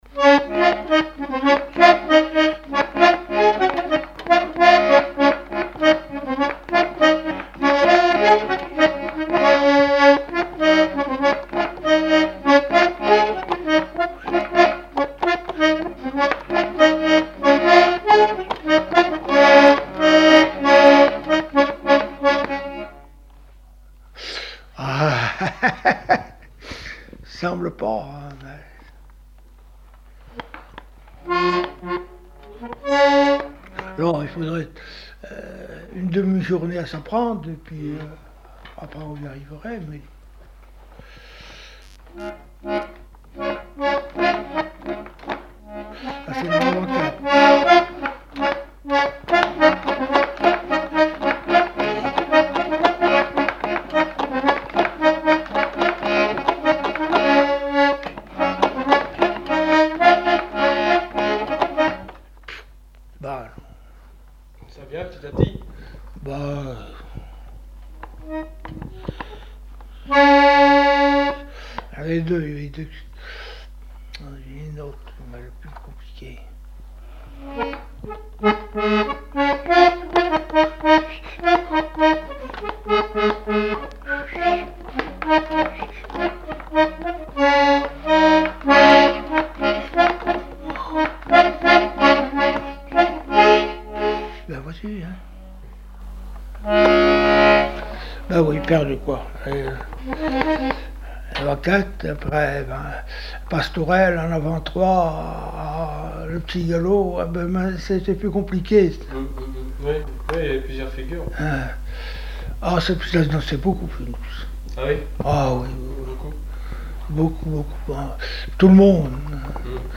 danse : quadrille : avant-quatre
Répertoire instrumental à l'accordéon diatonique
Pièce musicale inédite